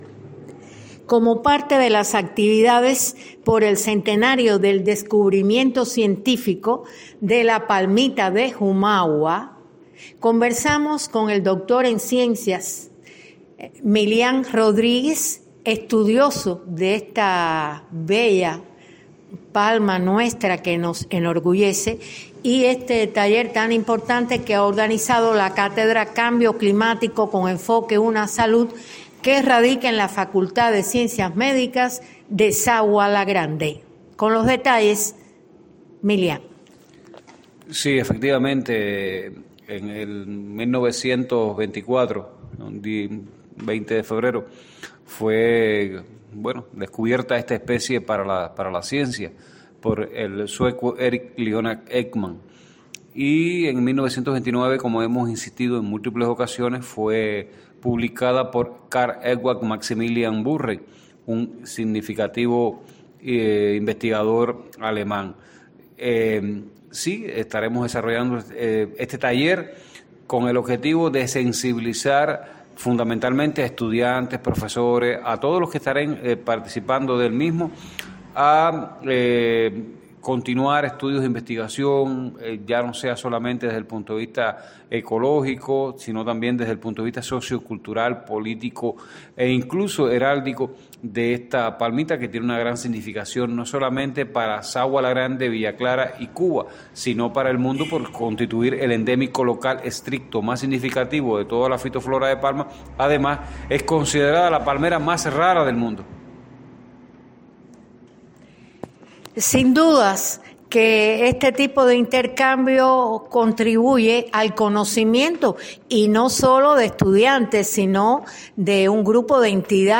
dialoga con el